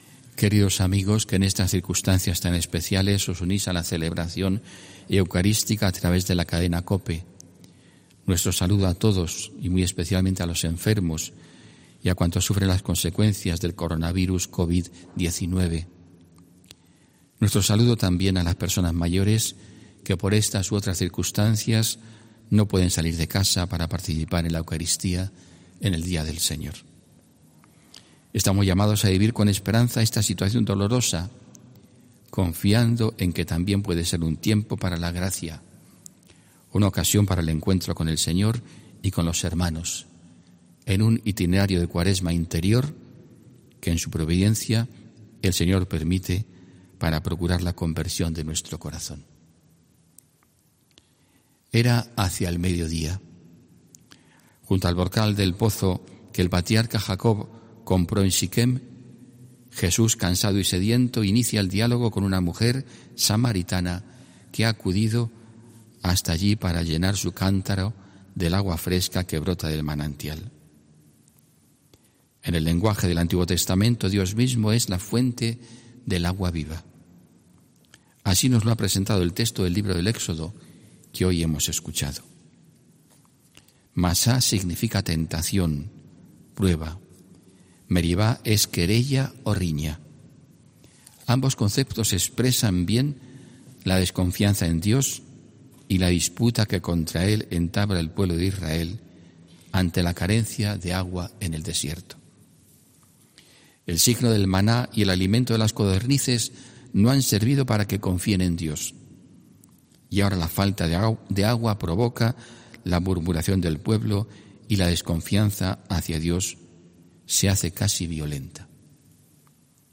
HOMILÍA 15 MARZO 2020